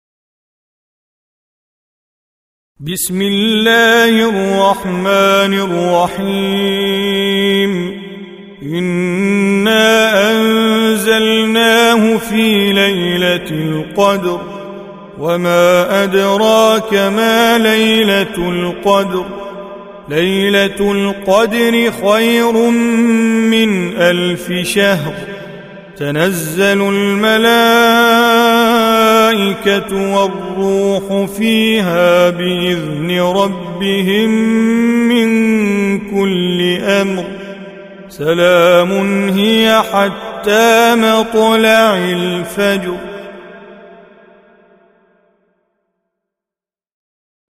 Audio Quran Tajweed Recitation
Surah Sequence تتابع السورة Download Surah حمّل السورة Reciting Mujawwadah Audio for 97. Surah Al-Qadr سورة القدر N.B *Surah Includes Al-Basmalah Reciters Sequents تتابع التلاوات Reciters Repeats تكرار التلاوات